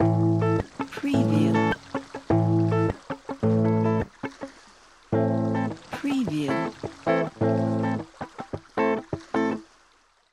ریتم بستکی 1 گیتار الکتریک | دانلود لوپ بستکی گیتار الکتریک
ریتم بستکی 1 گیتار الکتریک | دو نوع فیگور متفاوت از ریتم بستکی اجرا شده توسط گیتار الکتریک با کیفیت بالا | 24 آکورد اصلی برای استفاده در تمام گام های موسیقی
demo-rtm1-bastaki-electric-guitar.mp3